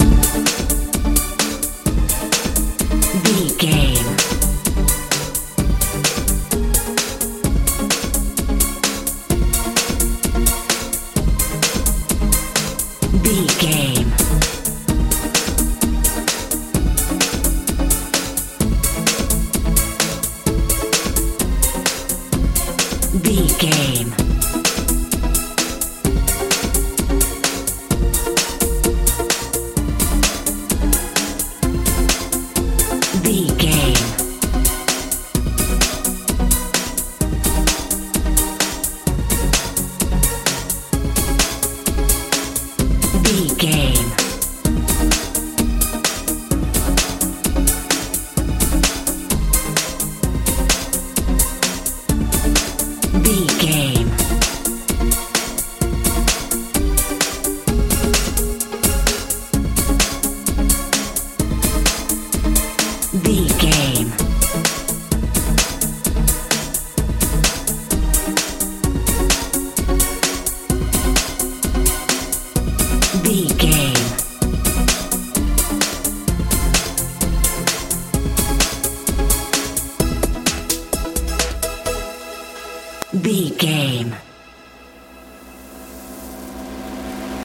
modern dance feel
Ionian/Major
E♭
confused
strange
synthesiser
bass guitar
drums
80s
suspense
anxious